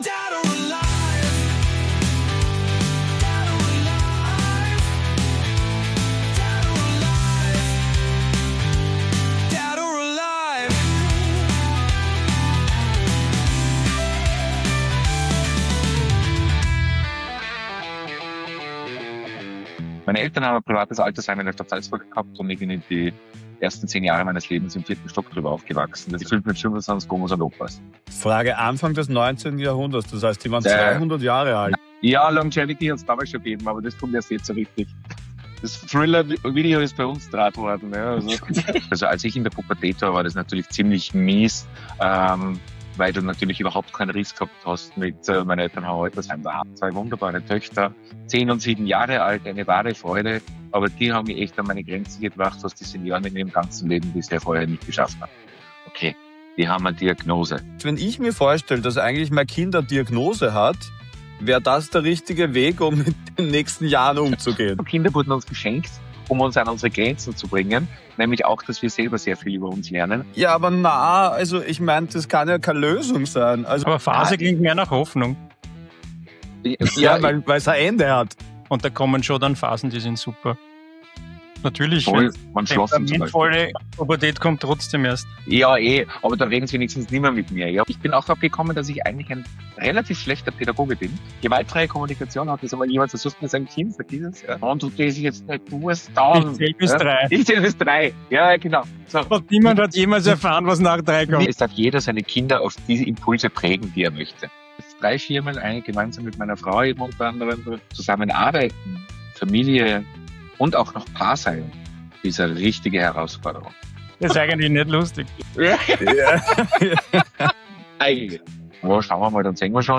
Aufgewachsen im Altersheim. Heute zweifacher Papa. Ein ehrliches Gespräch über Fürsorge, Verantwortung, Care-Arbeit, Beziehung & warum im Leben wirklich alles nur Phasen sind.